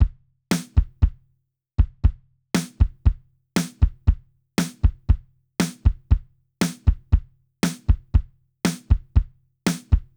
Save Me-drms.wav